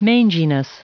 Prononciation du mot manginess en anglais (fichier audio)
Prononciation du mot : manginess